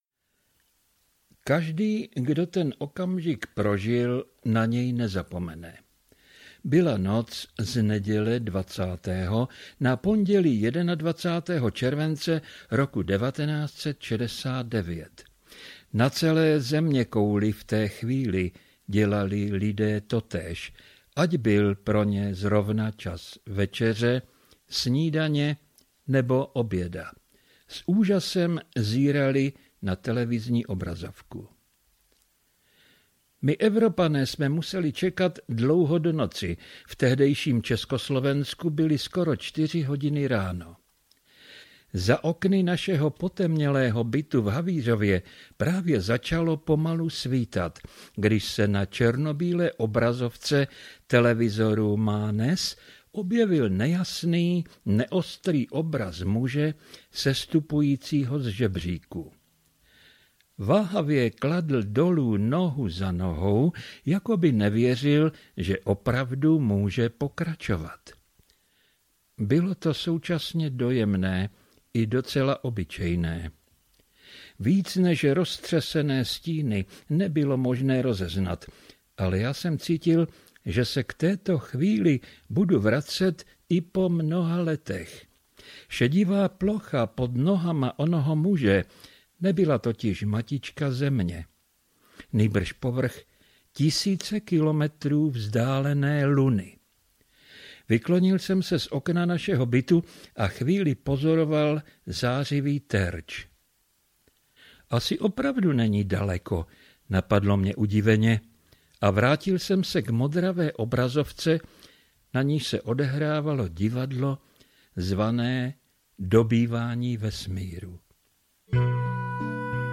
Zapomenuté výpravy audiokniha
Ukázka z knihy